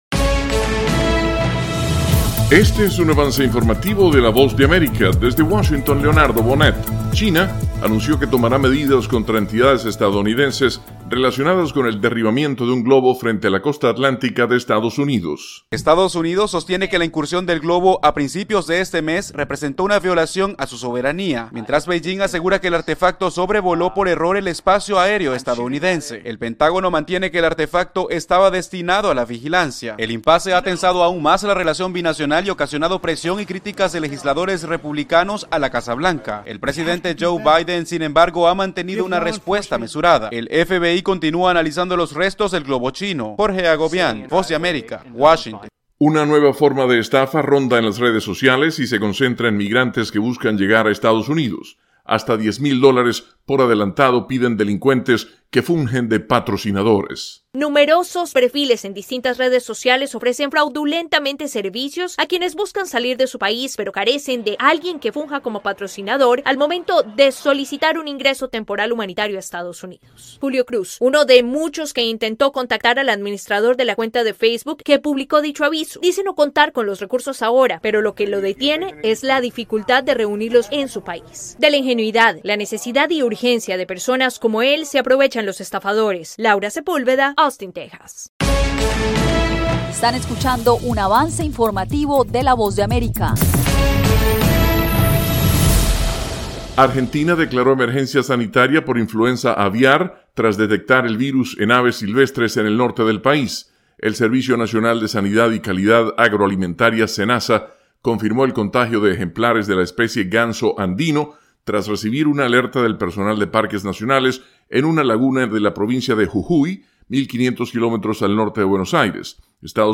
El siguiente es un avance informativo presentado por la Voz de América, desde Washington,